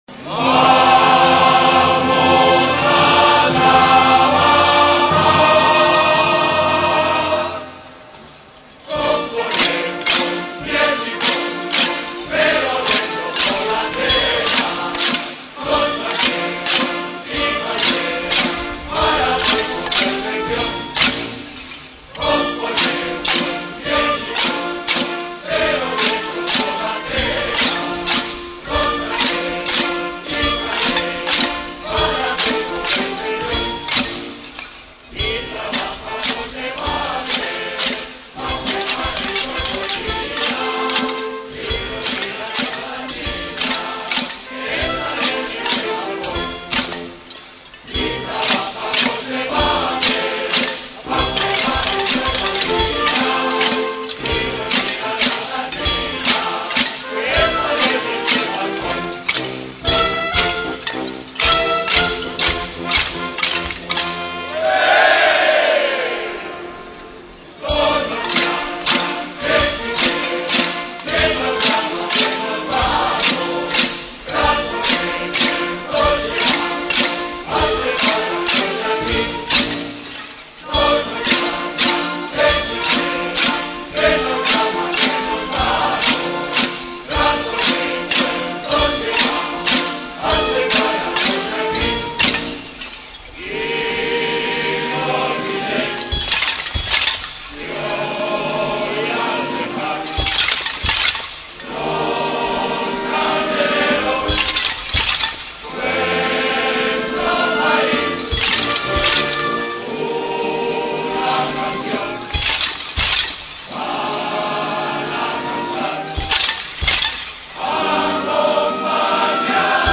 Mazurka
Mazurka.mp3